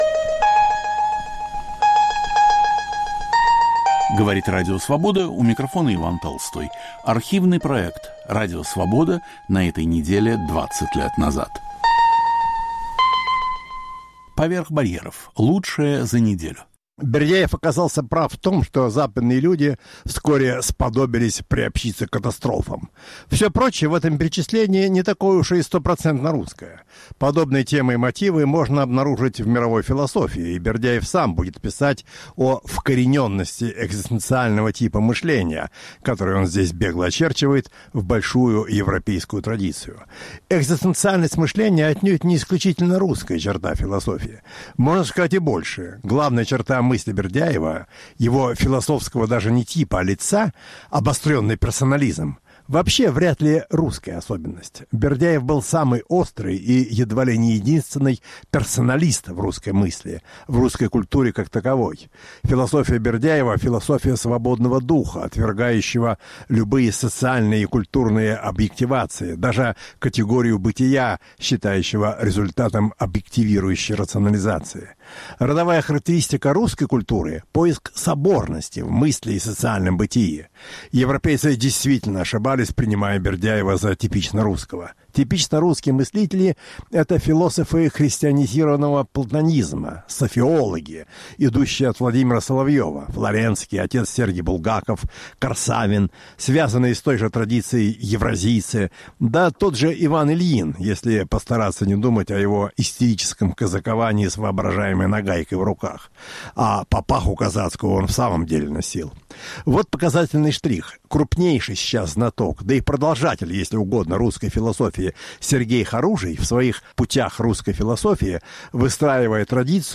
Ведущий Алексей Цветков.